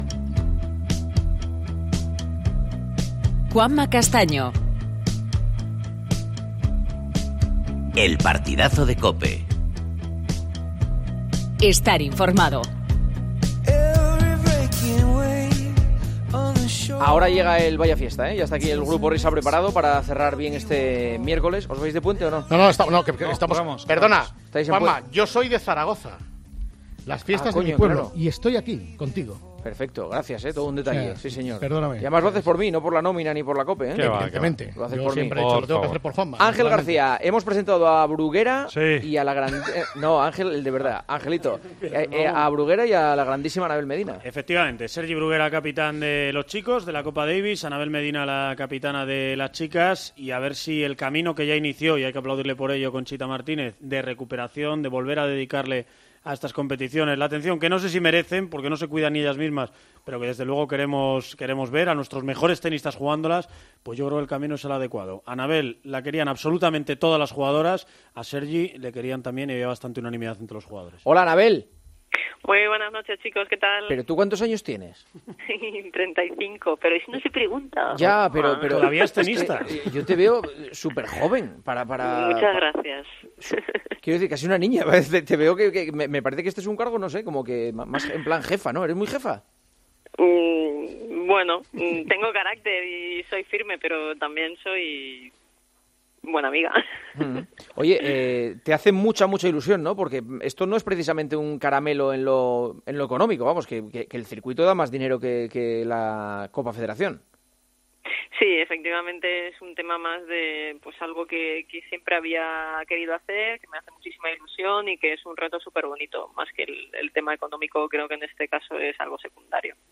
Entrevistas en El Partidazo de COPE
Entrevista a la nueva capitana del equipo español de Copa Federación de Tenis: "En lo económico pierdo dinero, pero lo que significa representar a España es mucho más fuerte que una cantidad económica"